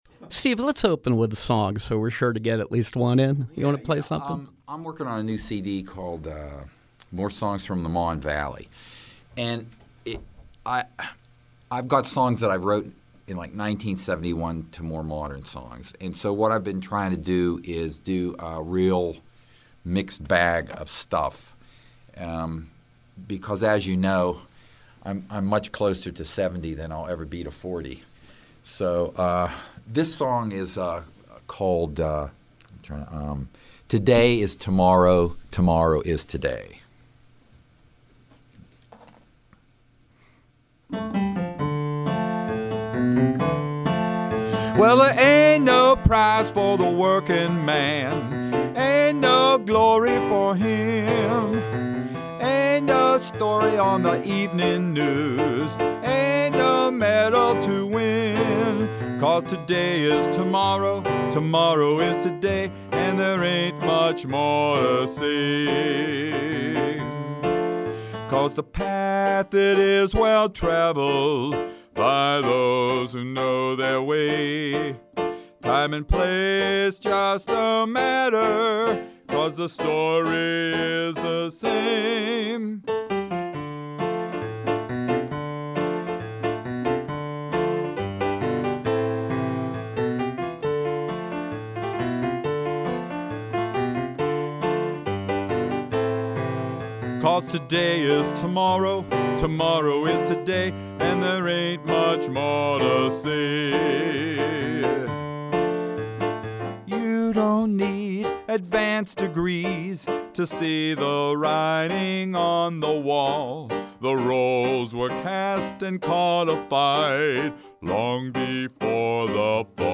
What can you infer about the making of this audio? Live Music Live music and conversation